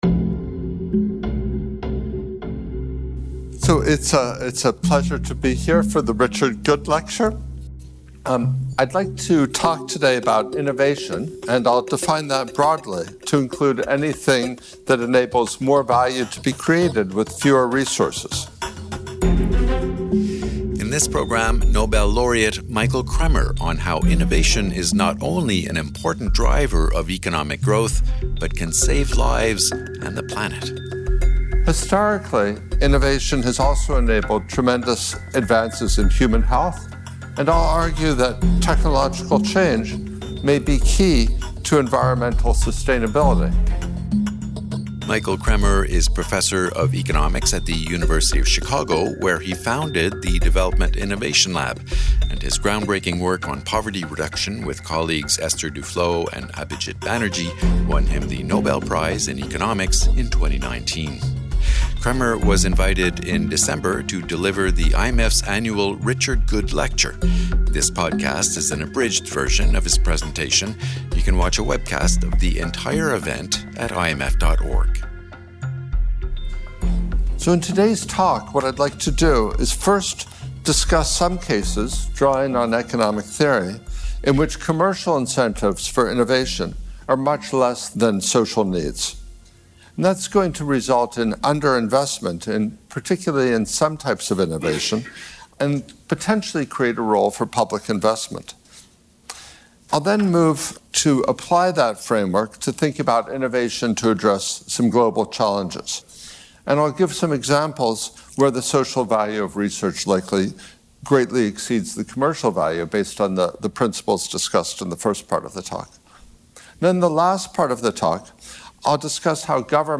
Michael Kremer was invited to deliver the IMF Richard Goode Lecture , an annual event to discuss policy issues and debates. In his talk, Kremer says commercial incentives for innovation are not always aligned with social needs, which results in underinvestment in some types of innovation and creates a role for public investment.